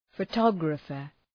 Προφορά
{fə’tɒgrəfər}